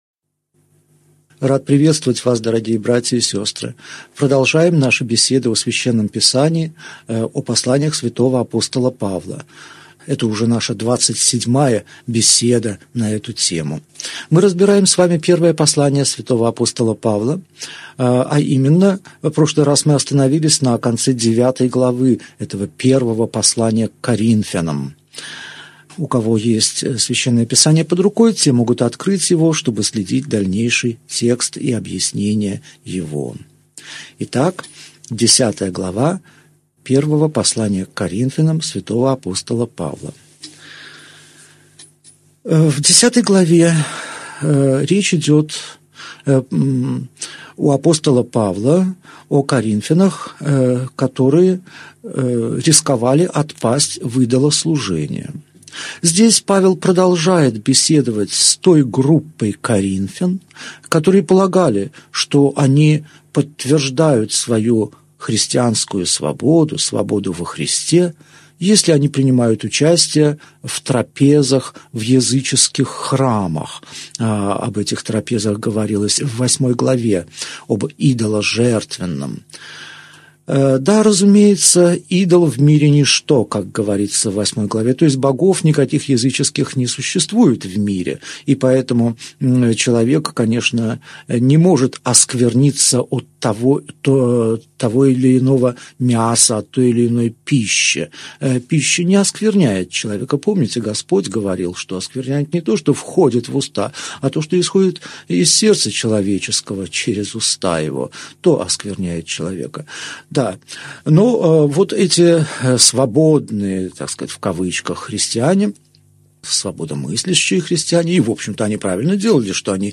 Аудиокнига Беседа 27. Первое послание к Коринфянам. Глава 9 | Библиотека аудиокниг